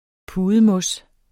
Udtale [ ˈpuːðəˌmɔs ]